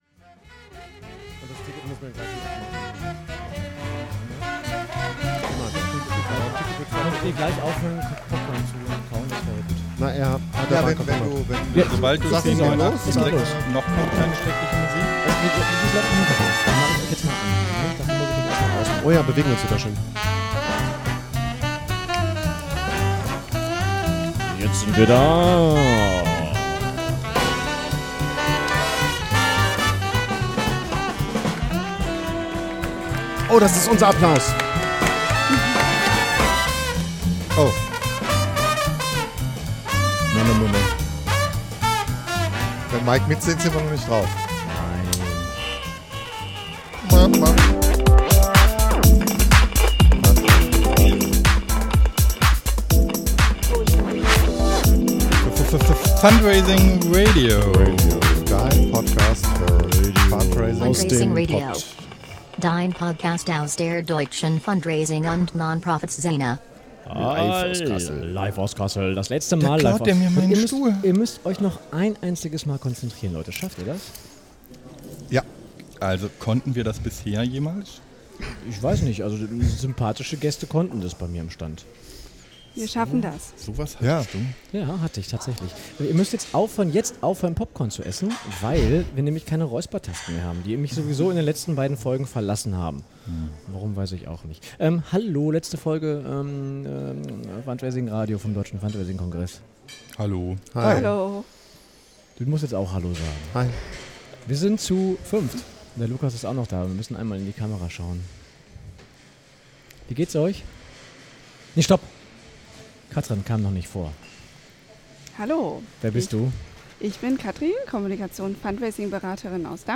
Im Anschluss gibt es Schulnoten und zuletzt einen Ausblick auf das, was vielleicht 2018 kommen könnte. Live von der Ausstellungsfläche des Deutschen Fundraising Kongresses, der zu diesem Zeitpunkt schon gar keiner mehr war. Podcasting improvisiert.